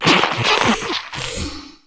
spidops_ambient.ogg